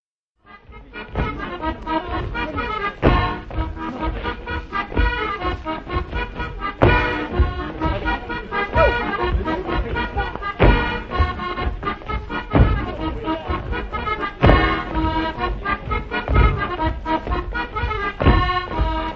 Challans
danse : branle : courante, maraîchine
Pièce musicale inédite